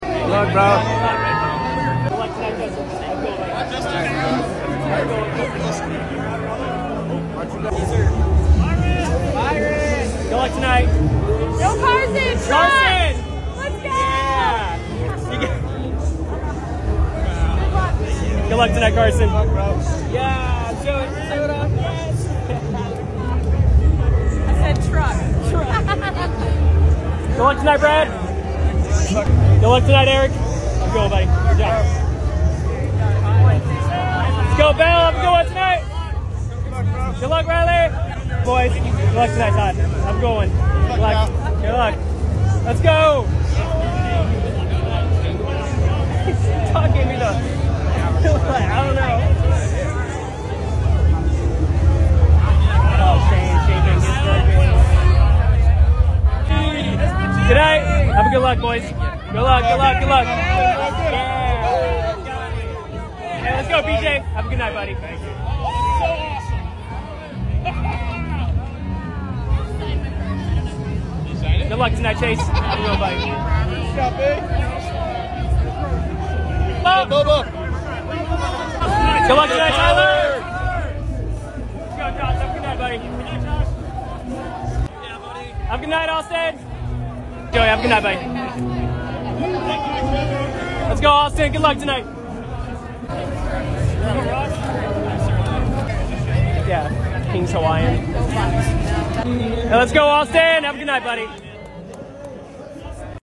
The NASCAR Cup Series drivers heading to introductions before the Coke Zero Sugar 400 at the Daytona International Speedway